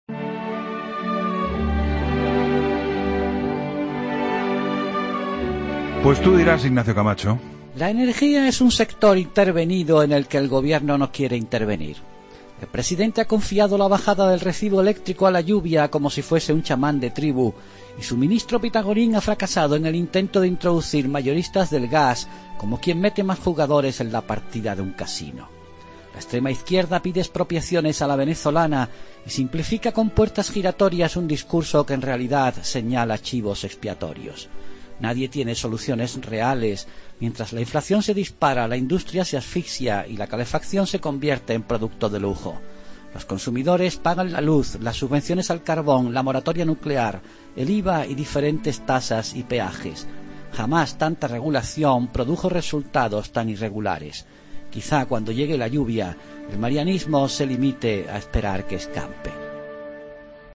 El comentario de Ignacio Camacho en 'La Linterna' sobre los precios del gas y la luz